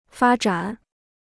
发展 (fā zhǎn): development; growth.
发展.mp3